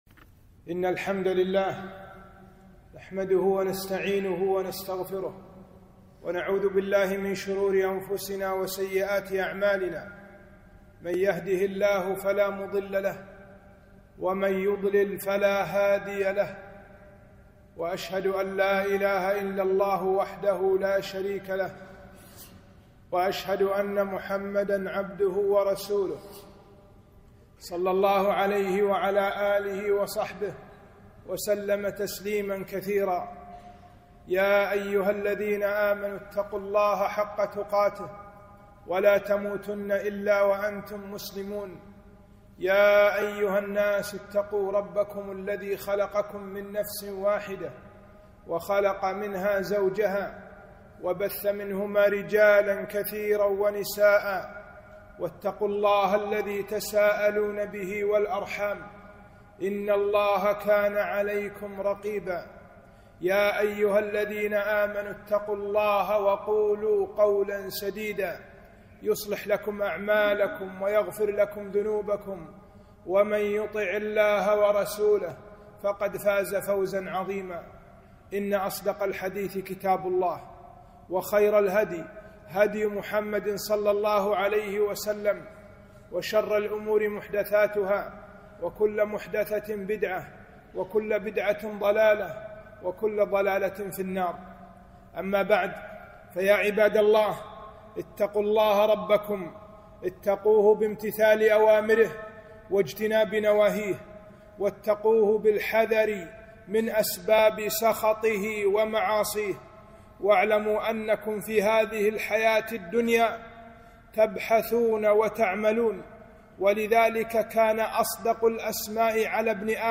خطبة - أهمية انشراح الصدر وأسباب